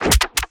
GUNMech_Rocket Launcher Reload_09_SFRMS_SCIWPNS.wav